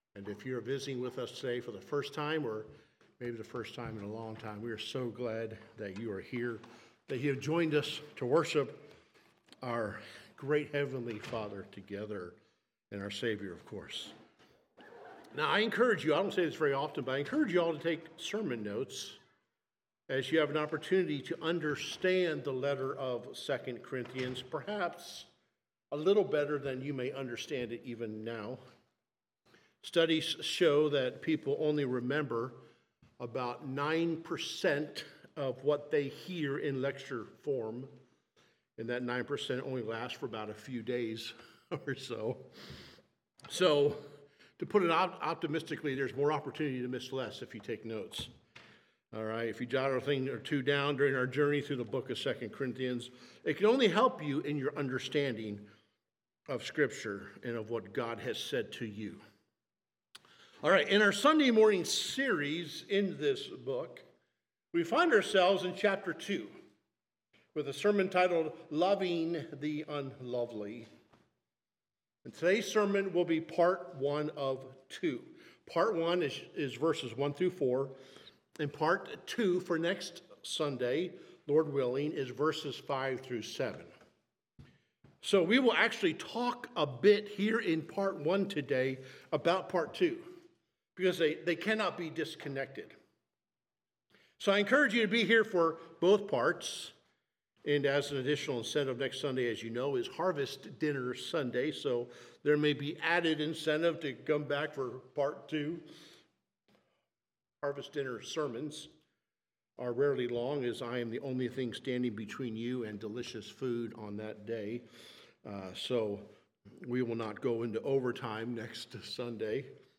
Sermons | Highland Baptist Church